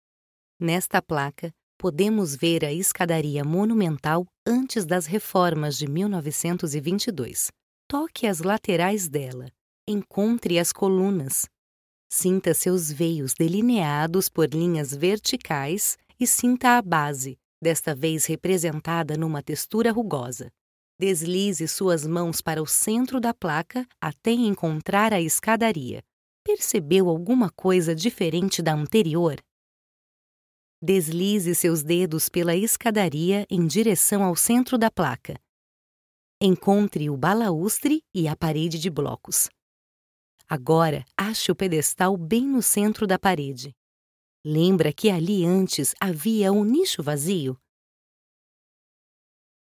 Audio Description
With a versatile, mature, and pleasant voice, I am able to adapt my narration style to the needs of the project, creating a unique and engaging experience for the listener.
With an excellent acoustic treatment system that offers excellent quality.